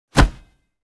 جلوه های صوتی
دانلود آهنگ تصادف 43 از افکت صوتی حمل و نقل
دانلود صدای تصادف 43 از ساعد نیوز با لینک مستقیم و کیفیت بالا